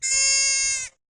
deer-DPSlVch4.ogg